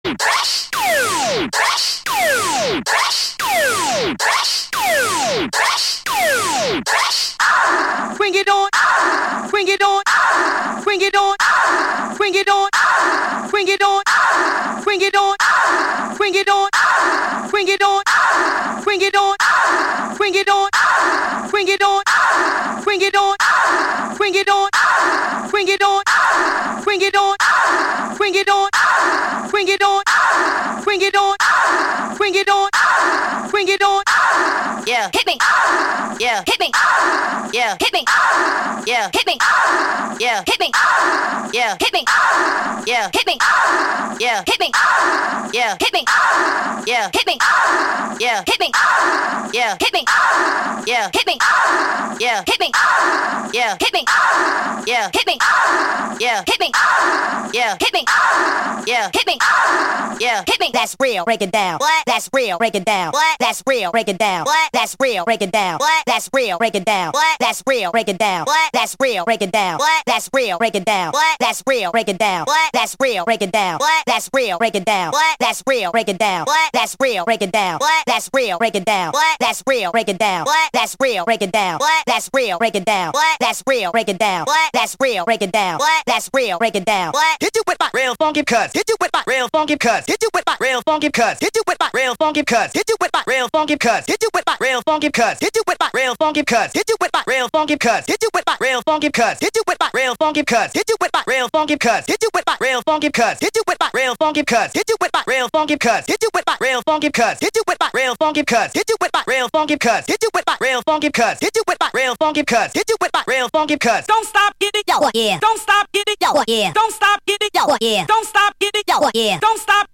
“83 BPM Non Skip Loops” (3:34)